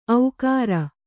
♪ aukāra